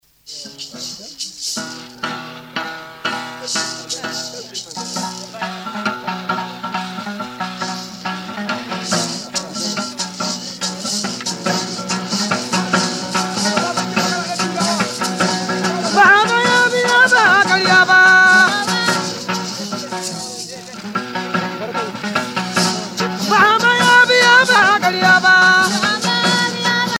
gestuel : danse